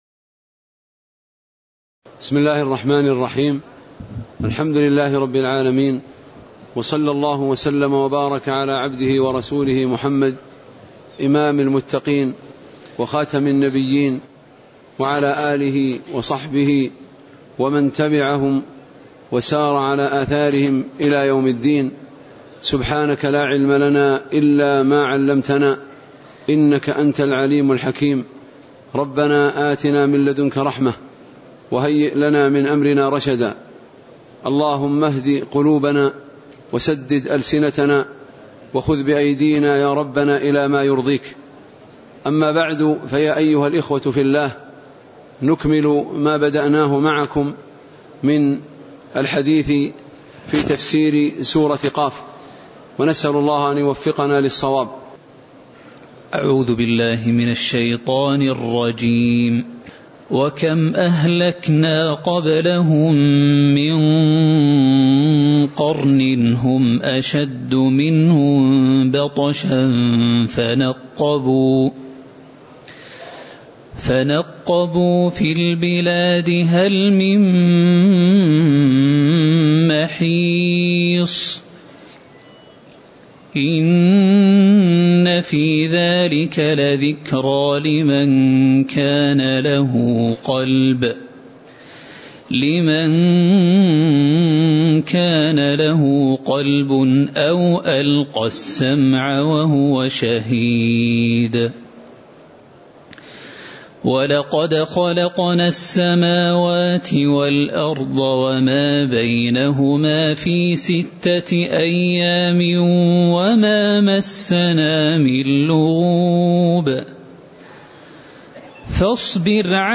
تاريخ النشر ٢٩ ربيع الثاني ١٤٣٩ هـ المكان: المسجد النبوي الشيخ